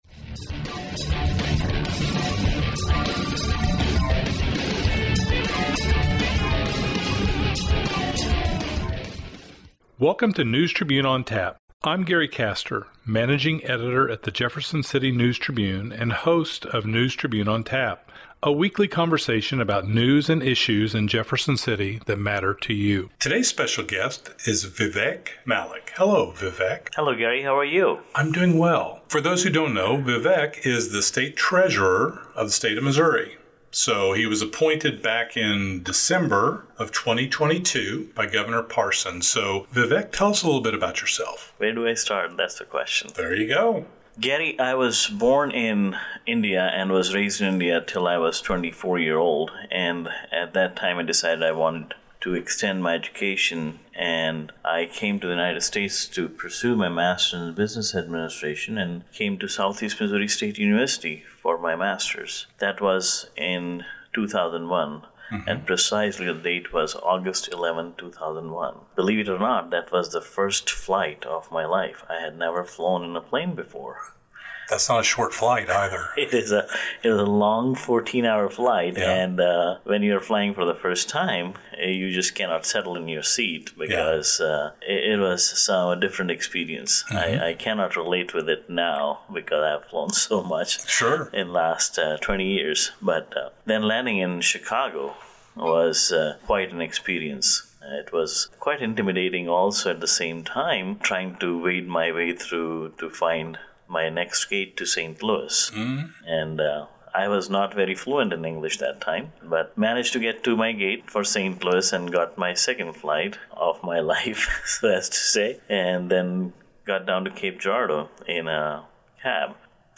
chats with Missouri State Treasurer Vivek Malek about his journey from India to the United States, his role as the state treasurer and some of the programs and services the treasurer's office offers, including MOBUCK$, MOScholars, and unclaimed property.